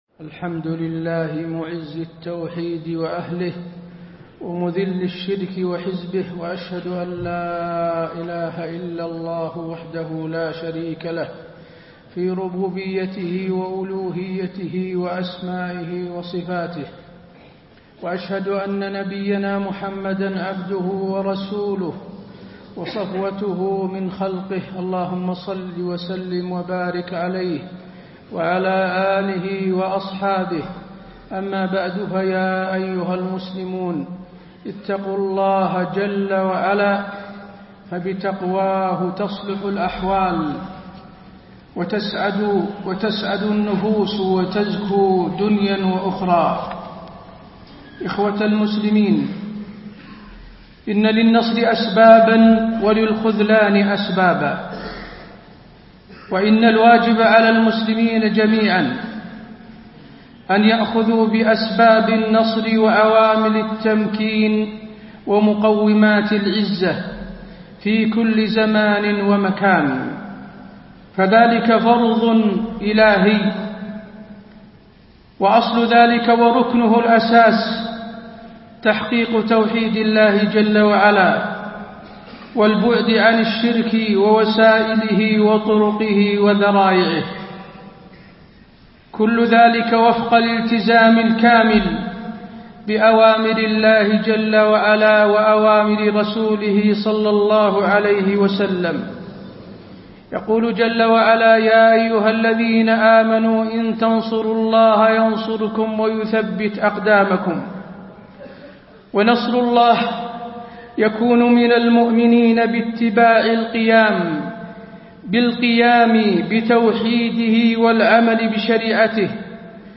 تاريخ النشر ١٢ شعبان ١٤٣٤ هـ المكان: المسجد النبوي الشيخ: فضيلة الشيخ د. حسين بن عبدالعزيز آل الشيخ فضيلة الشيخ د. حسين بن عبدالعزيز آل الشيخ الأخذ بأسباب القوة والتمكين